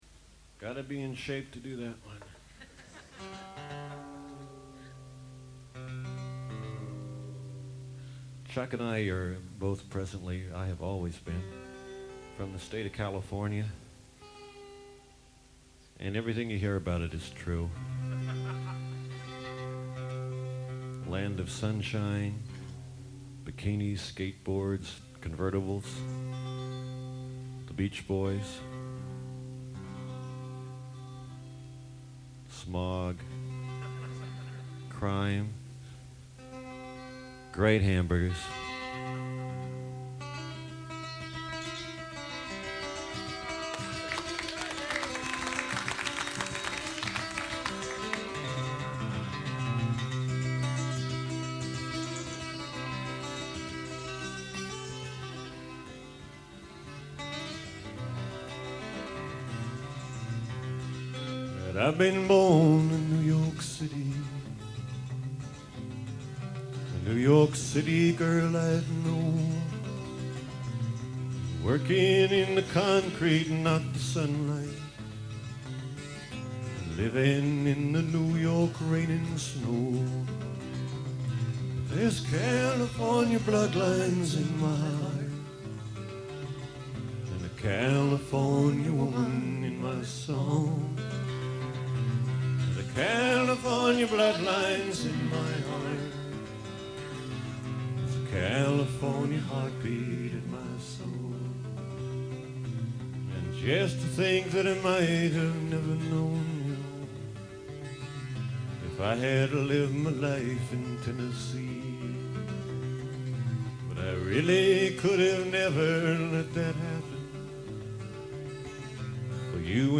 concert in Merseyside, England.
a great medley
Ringing guitars and great harmony.